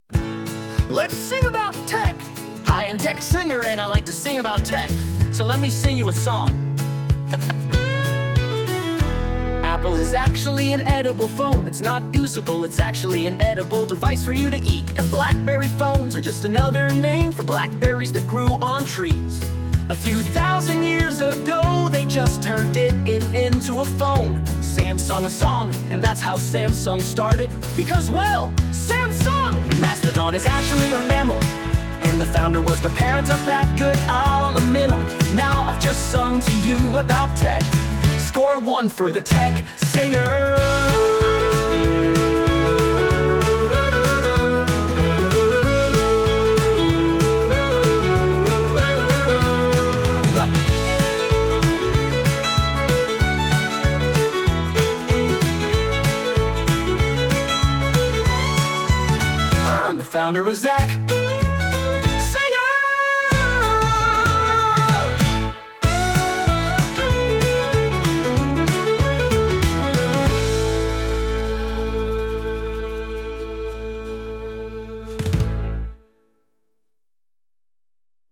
here's Microsoft office365 outlook sound for web users